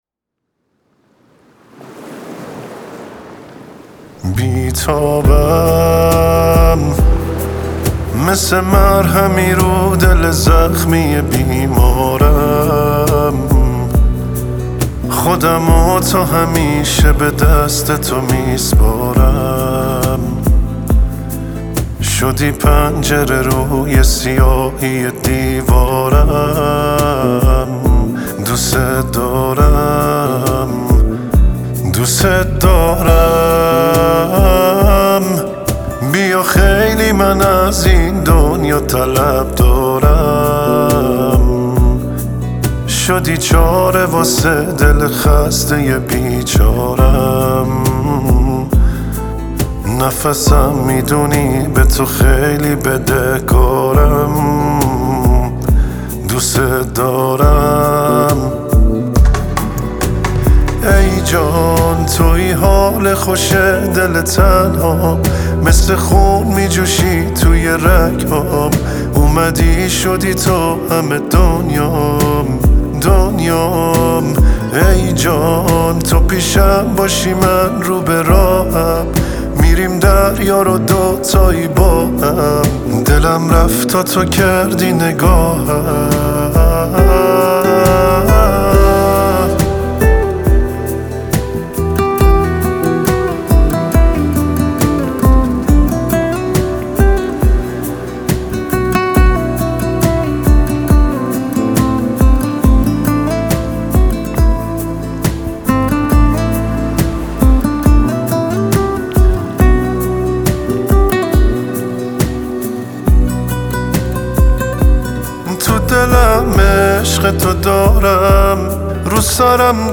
ترک عاشقانه